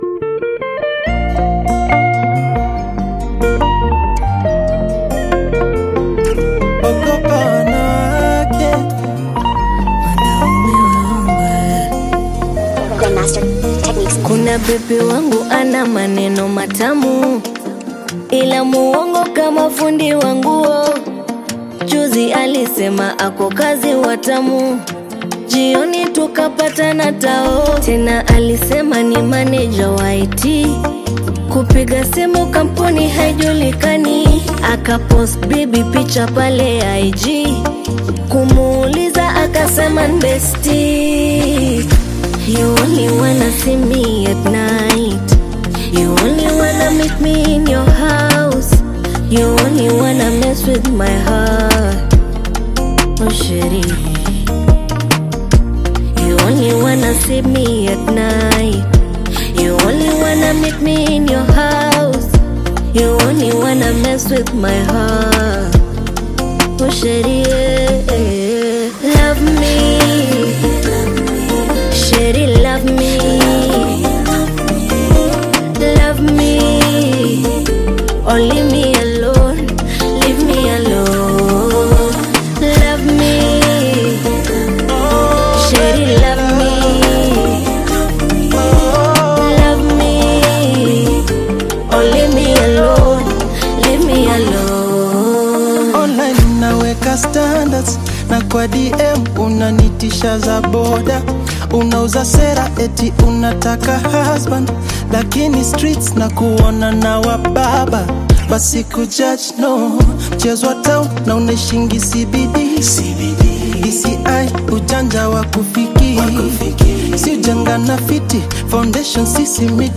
Kenyan Afro-pop star
Afrobeats